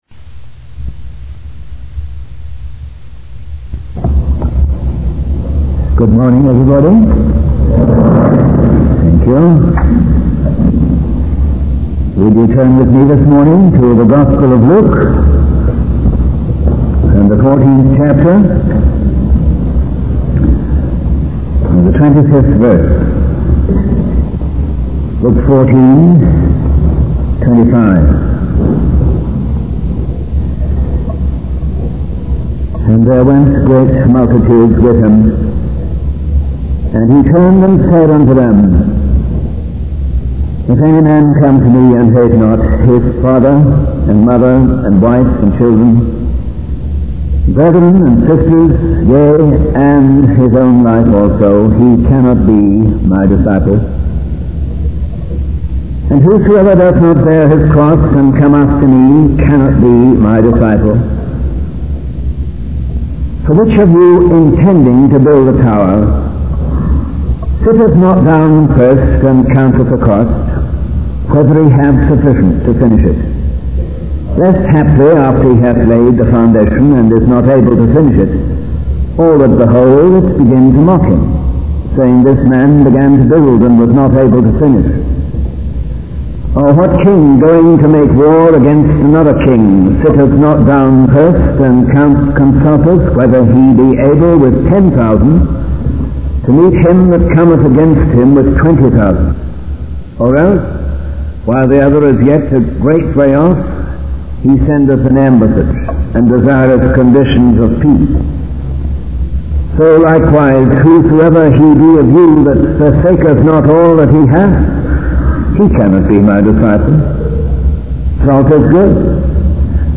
In this sermon, the speaker describes a scene in a home where the family gathers around a table tennis table to read the Bible and pray together. The speaker emphasizes the importance of maintaining a focus on mission and spreading the word of God, both locally and globally.